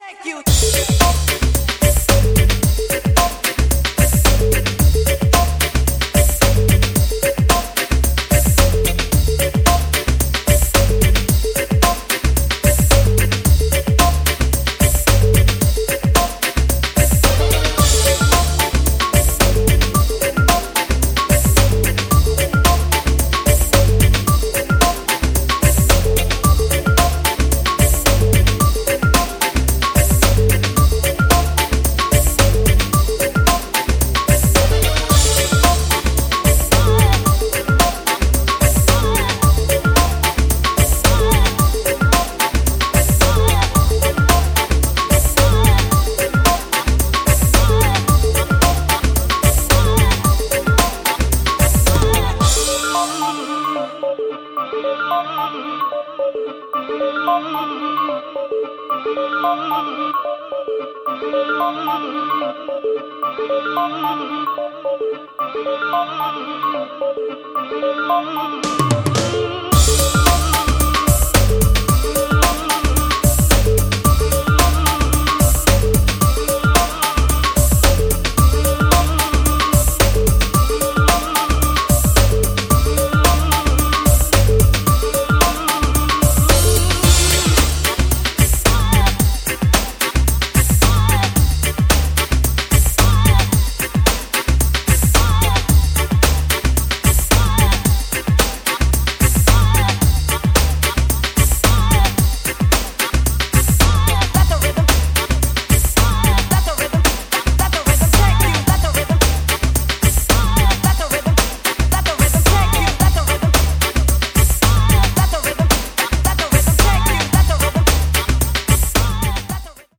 ちょっと遅めの110BPMで進行するポスト・ストリートソウル/ブレイクビーツ